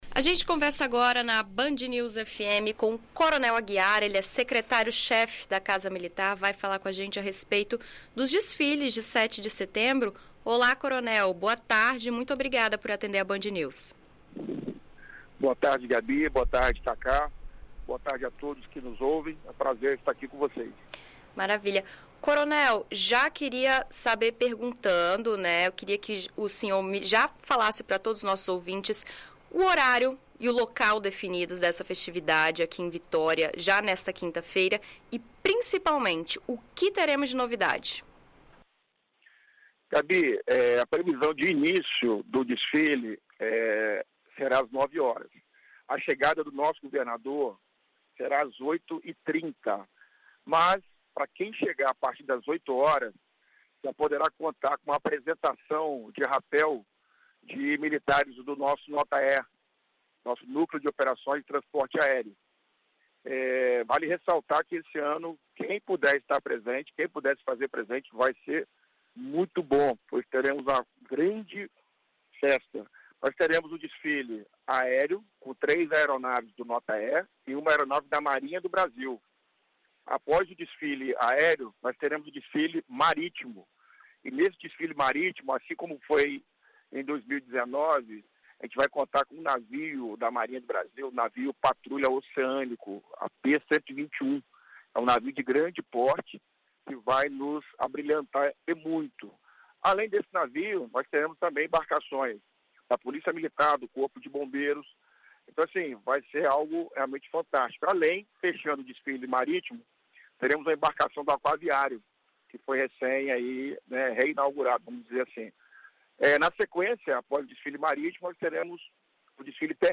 Em entrevista à BandNews FM ES nesta segunda-feira (04), o secretário chefe da Casa Militar, Jocarly Martins de Aguiar Júnior conta curiosidades sobre o desfile.